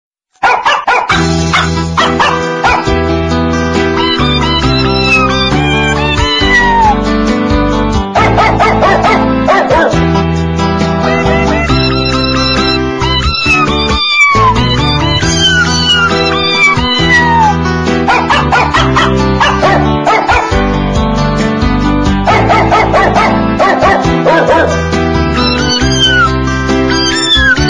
Catégorie: Animaux